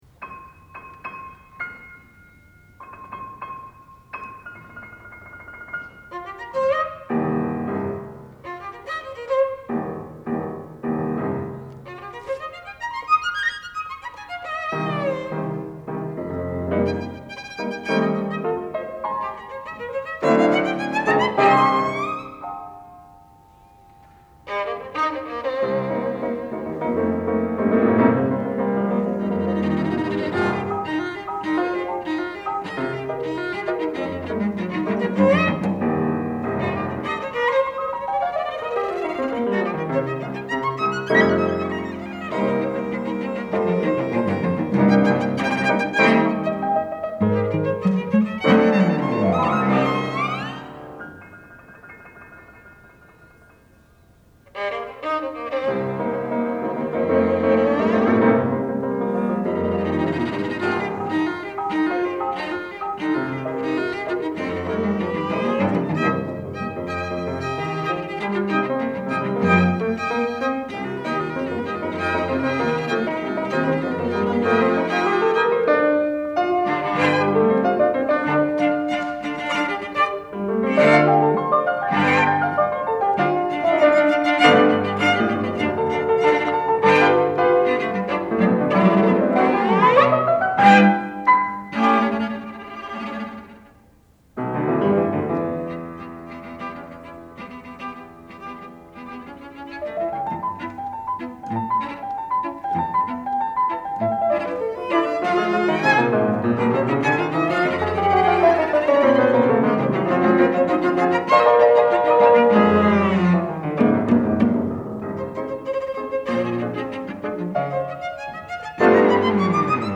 for Piano Trio (or Clarinet, Cello, and Piano) (1990)